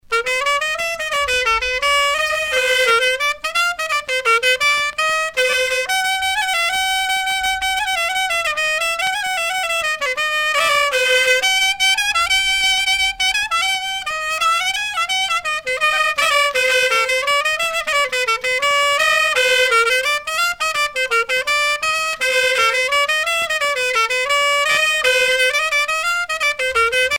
danse : plinn